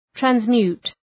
Shkrimi fonetik{trænz’mju:t, træns’mju:t}
transmute.mp3